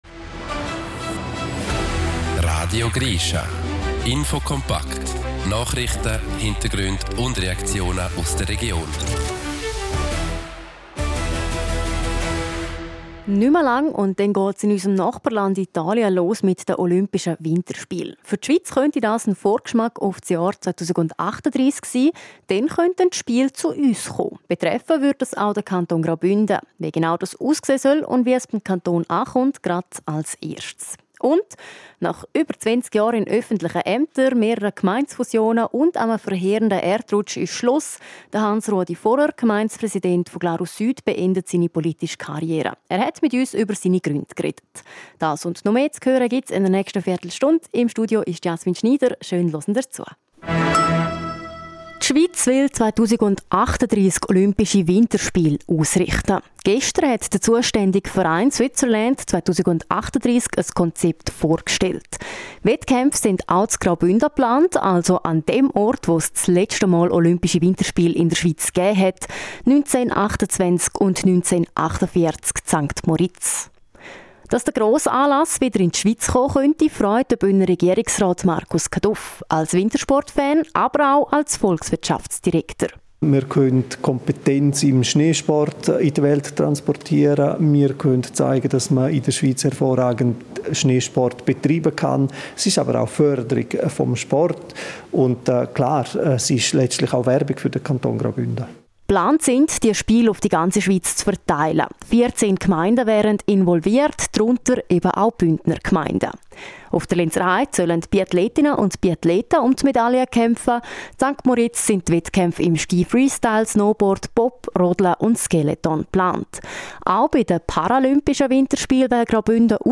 Weitere Reaktionen aus der Bevölkerung: Passantinnen und Passanten haben unterschiedliche Meinungen zu den Schweizer Olympiaplänen.